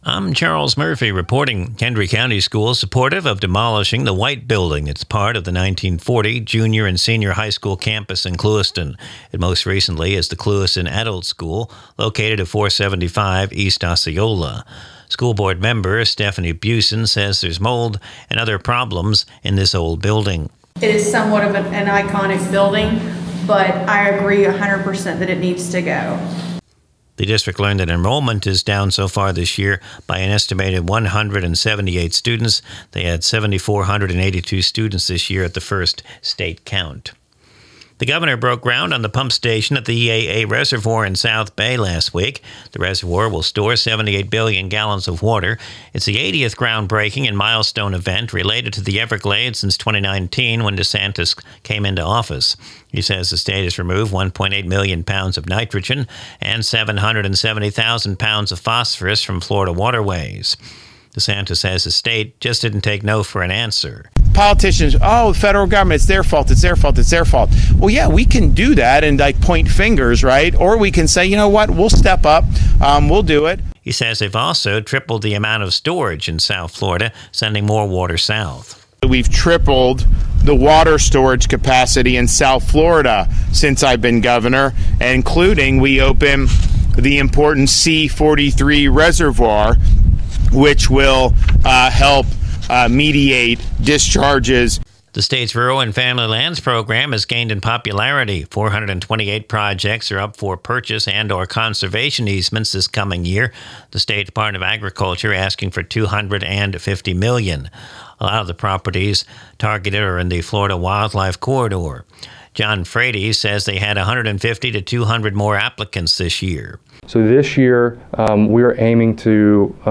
WAFC Local News – November 10, 2025
Recorded from the WAFC daily newscast (Glades Media).